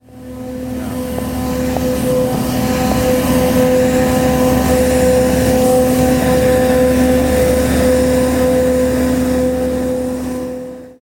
2+-+leafblower+alone.mp3